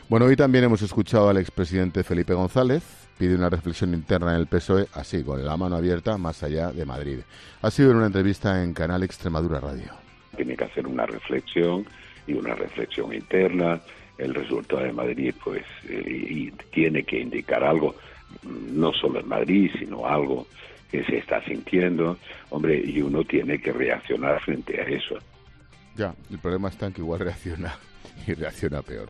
El presentador de 'La Linterna' comenta las declaraciones del expresidente del Gobierno después de la debacle electoral del PSOE en Madrid